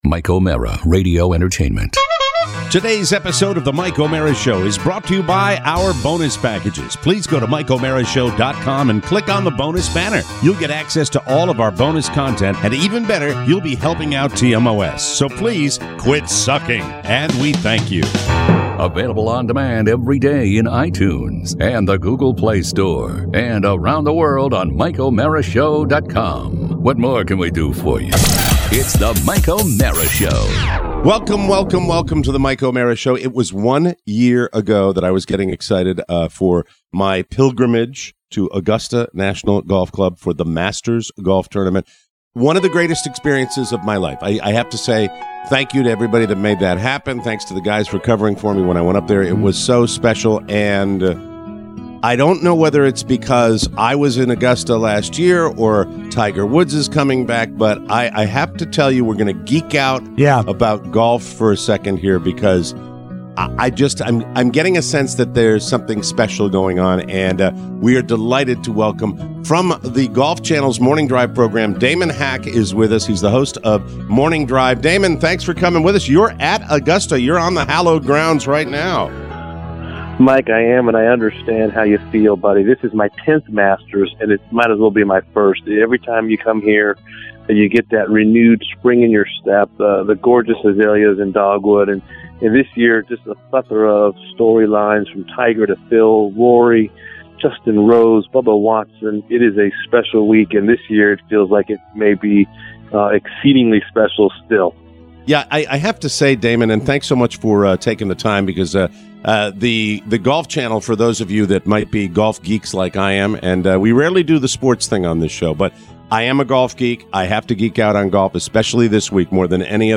#1979: Live From Augusta